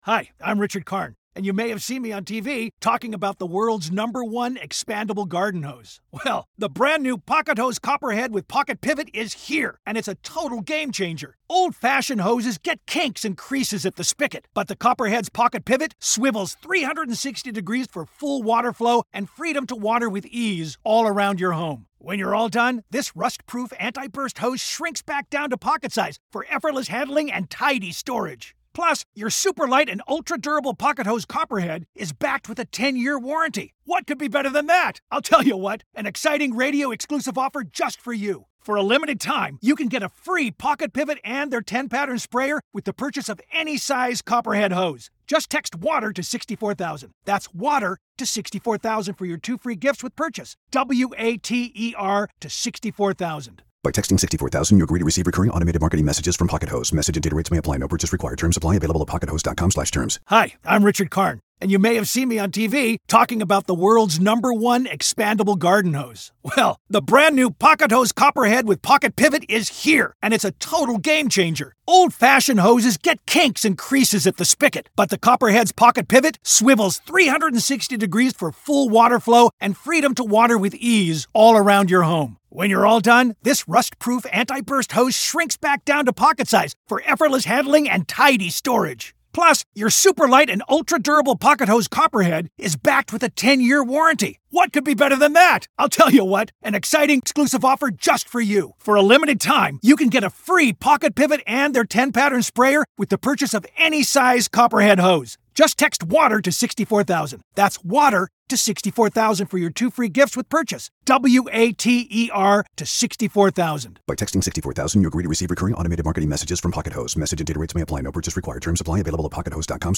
Comedian Roy Wood Jr. joins The Michael Steele Podcast.